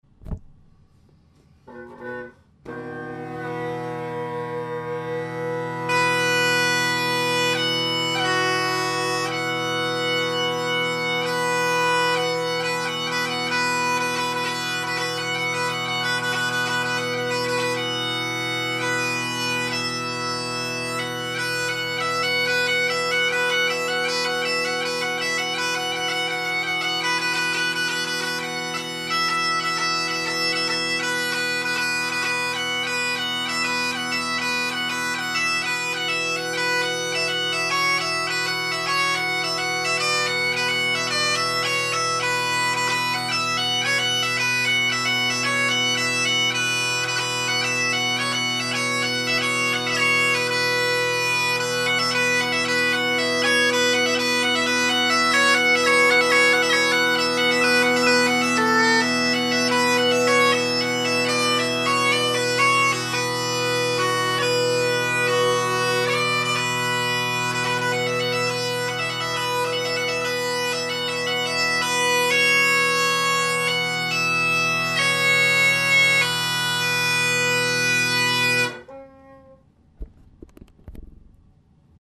Great Highland Bagpipe Solo
D is WAY out of tune and the low A was doing very funky things.
And here is some non-music I made up trying to only play mostly the in tune notes, F#, E, and B. Take note, those aren’t low A’s, they’re B’s! The drones are tuned to B, not A, except of course the baritone tuned to E.
nonmusicbagad.mp3